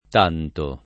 t#nto] agg., pron., avv.